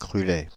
Crulai (French pronunciation: [kʁylɛ]
Fr-Crulai.ogg.mp3